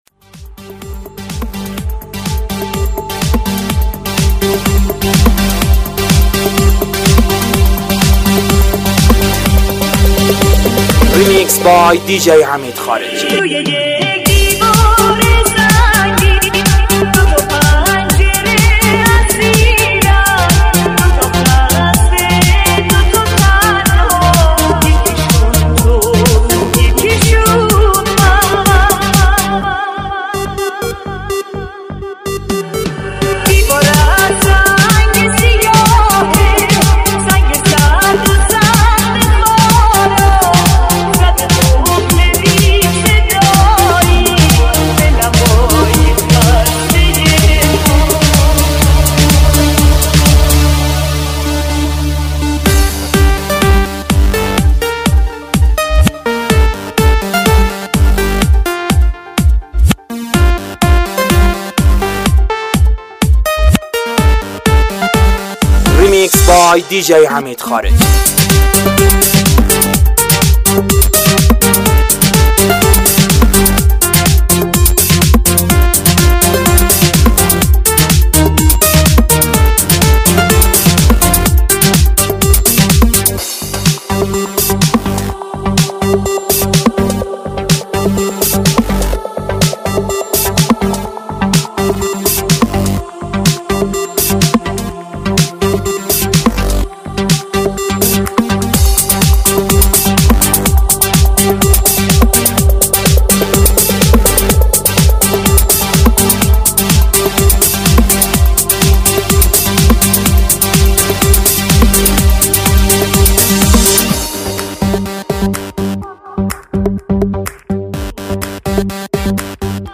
با فضایی نو و شنیدنی